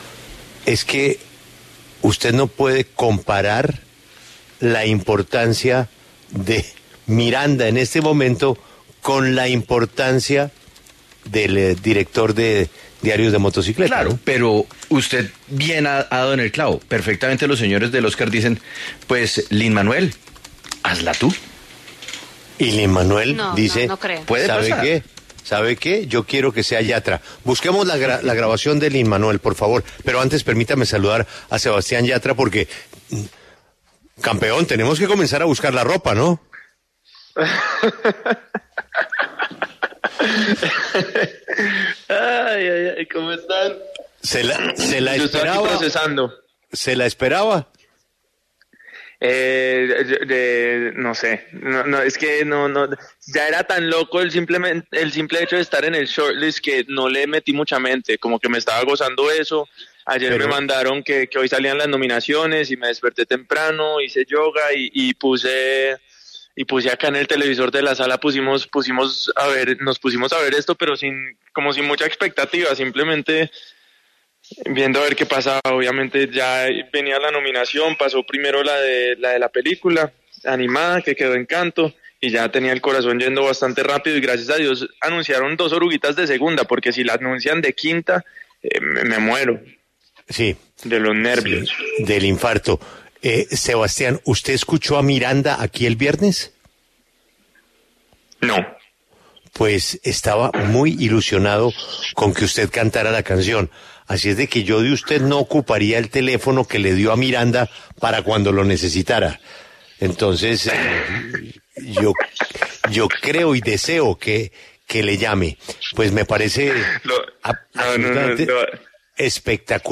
En el encabezado escuche la entrevista con Sebastián Yatra, quien interpreta “Dos oruguitas” en la película animada Encanto de Disney.